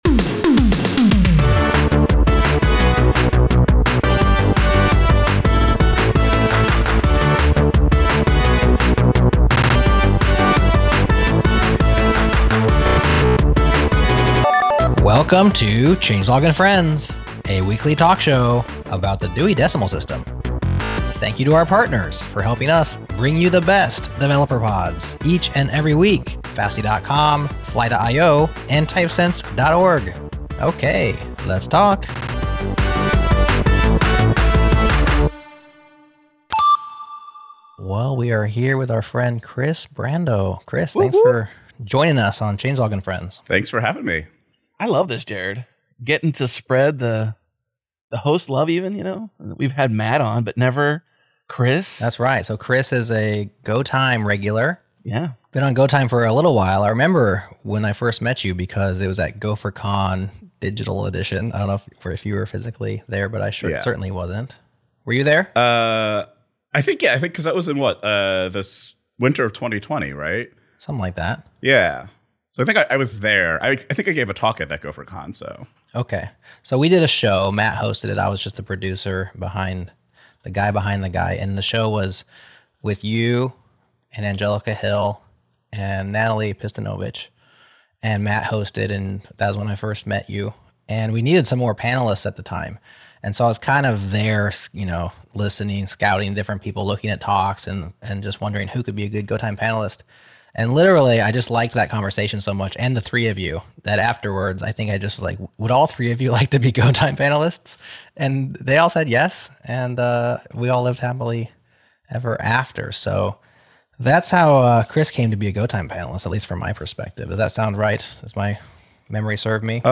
[ Changelog ] [ MP3 ] [ Spotify ] [ Apple Podcasts ] Transcript ( 141 segments) Export transcript to PDF 0:14 SPEAKER_01 Welcome to Changelog and Friends, a weekly talk show about the Dewey Decimal System.